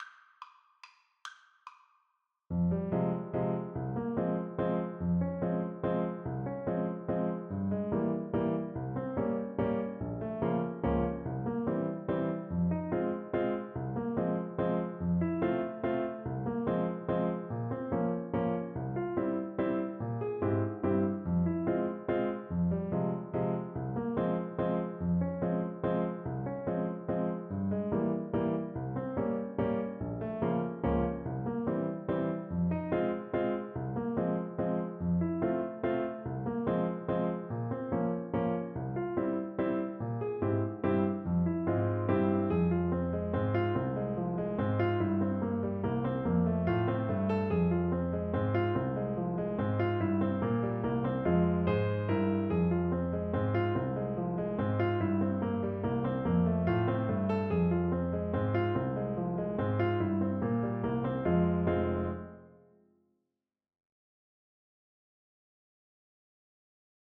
French Horn
Traditional Music of unknown author.
F minor (Sounding Pitch) C minor (French Horn in F) (View more F minor Music for French Horn )
Molto Allegro = c.144 (View more music marked Allegro)
3/4 (View more 3/4 Music)
Finnish